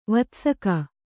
♪ vatsaka